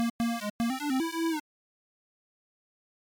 Level up sound
8-bit 8bit arcade beep cartoon chiptune level levelup sound effect free sound royalty free Gaming